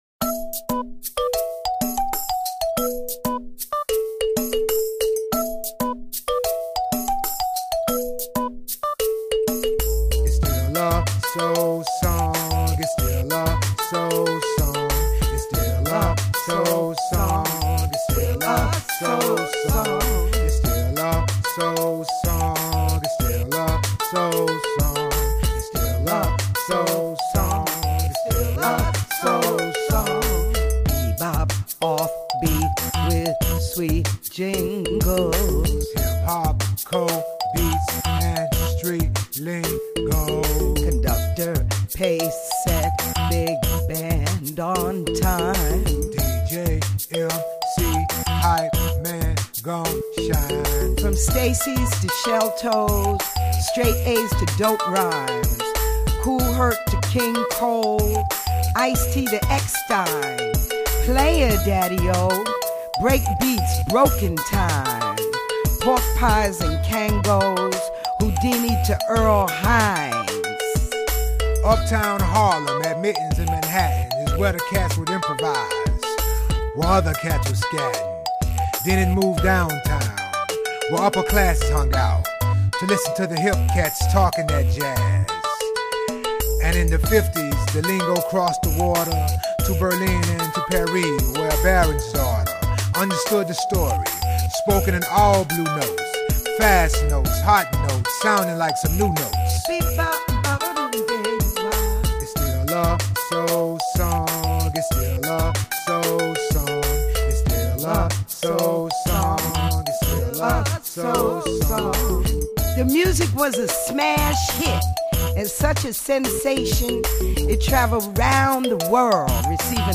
with her swinging brand of jazz and blues.